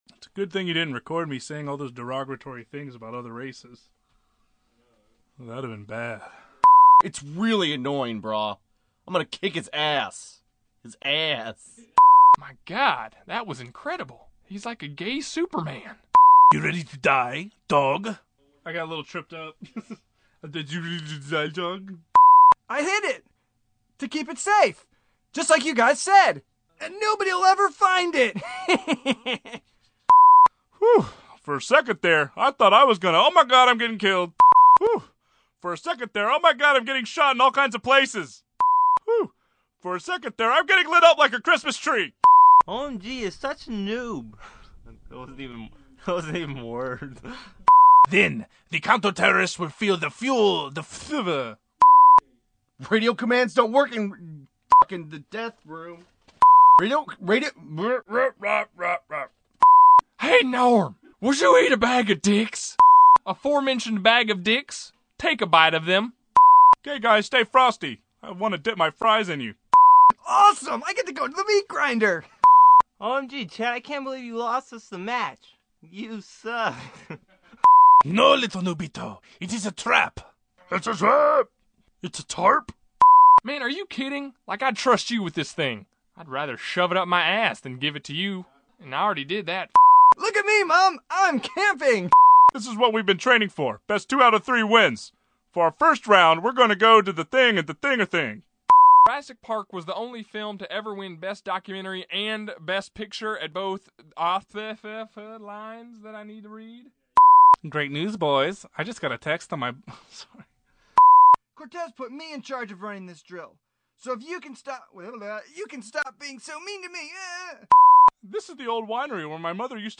Since you’ve all been kind of asking and secretly wishing, today I bring you Episode Four and Five audio outtakes.